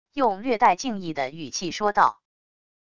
用略带敬意的语气说道wav音频